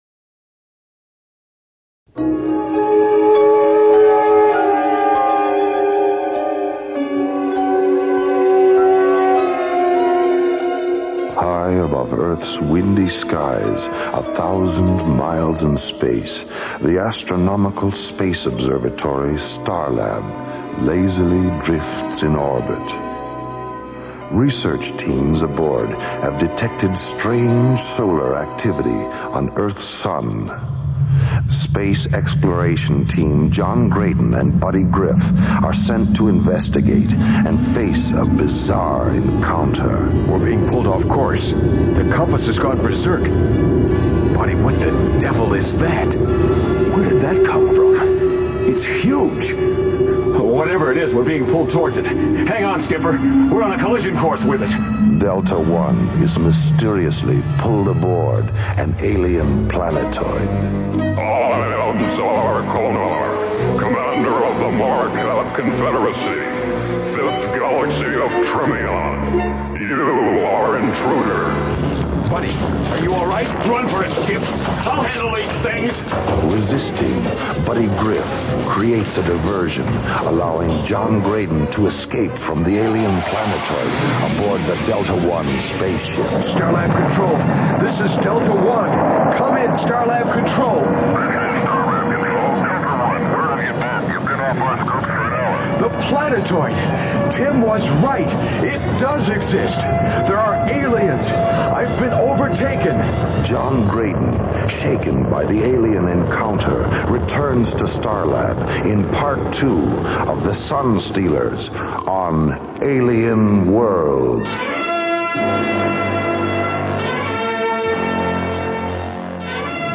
The show was ahead of its time, utilizing a documentary style of dialogue that immersed listeners in its interstellar adventures.
'Alien Worlds' was a syndicated radio show that brought together a blend of captivating narratives, realistic sound effects, and high production values, setting a new standard for audio drama.